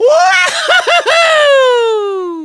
One of two voice clips from Mario in Super Mario Galaxy when he is launched.